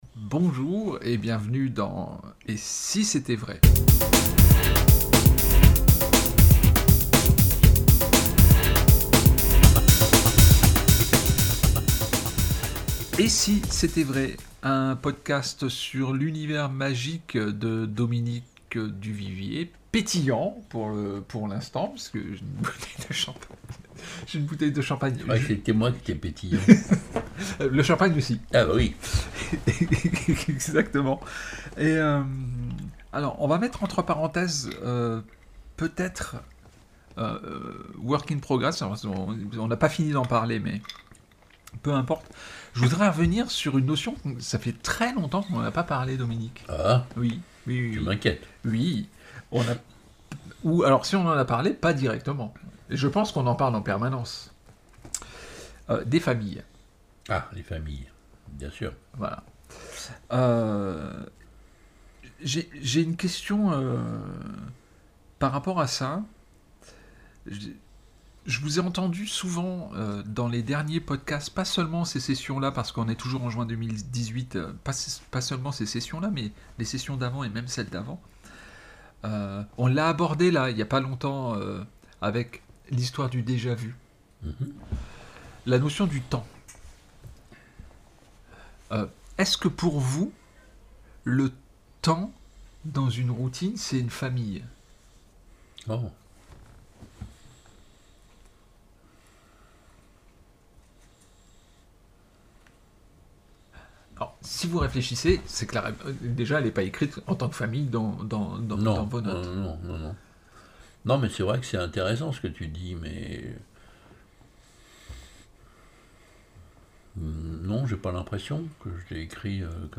Podcast « Et si c'était vrai ? » émission n°79 - Entretiens